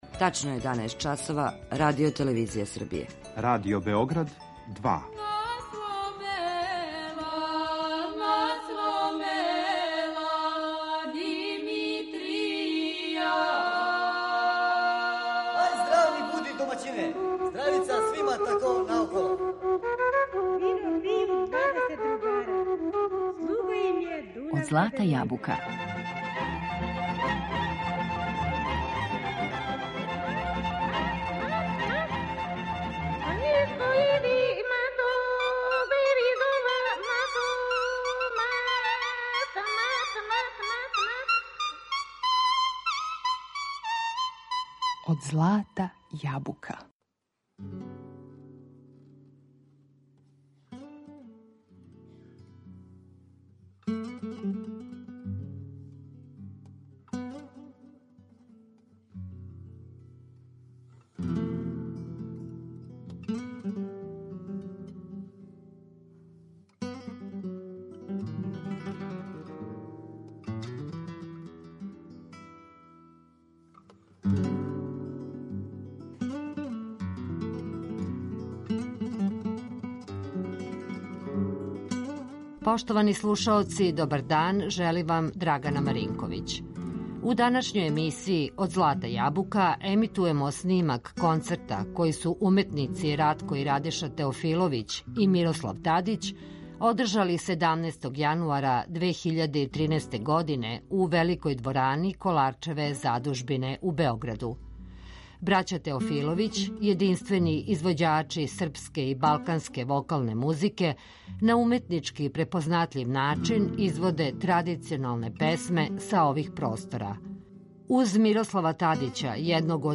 јединствени извођачи српске и балканске вокалне музике
класичних гитариста